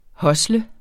Udtale [ ˈhʌslə ]